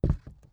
ES_Walk Wood Creaks 10.wav